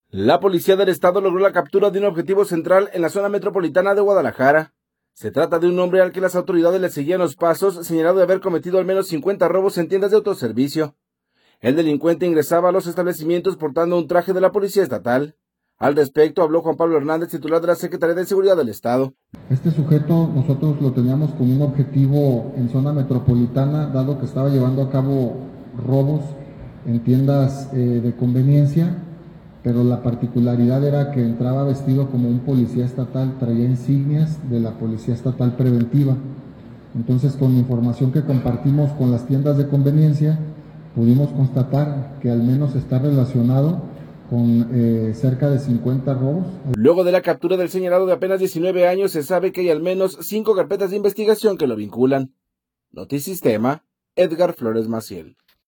Al respecto habló Juan Pablo Hernández, titular de la Secretaría de Seguridad del Estado.